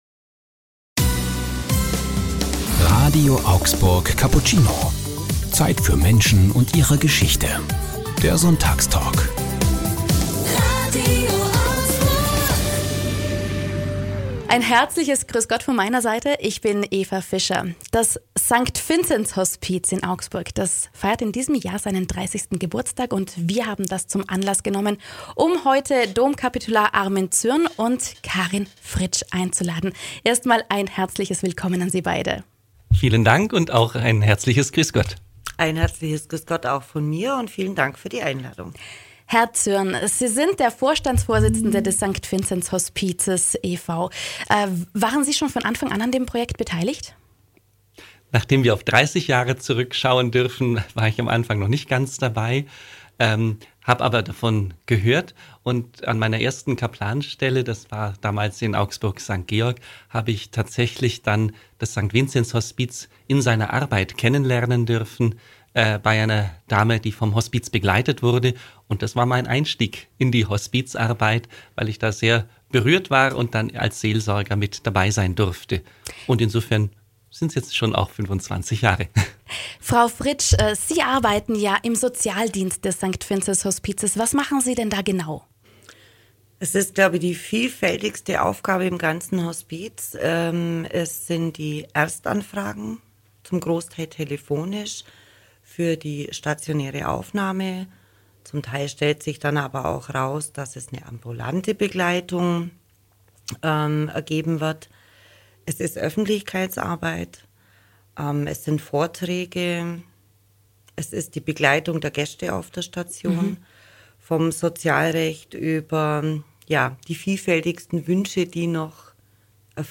Ein Gespräch über Leben und Tod: Sonntagstalk mit dem St. Vinzenz Hospiz ~ RADIO AUGSBURG Cappuccino Podcast